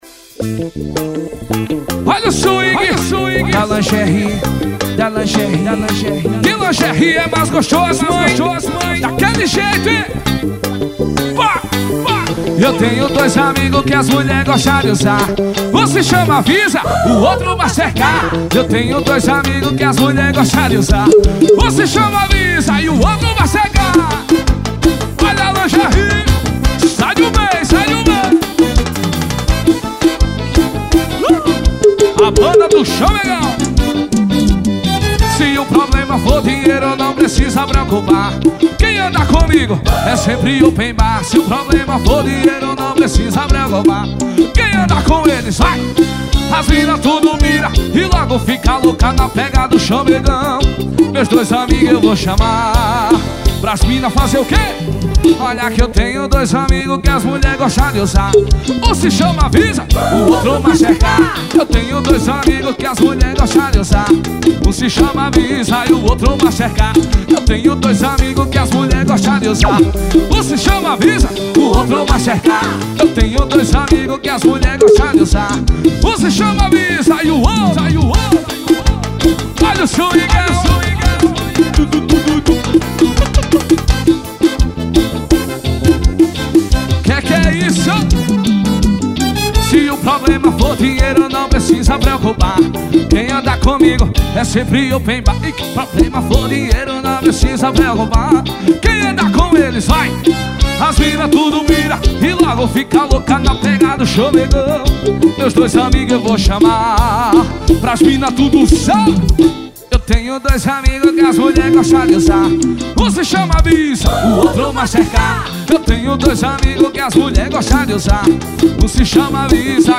AO VIVO 2014.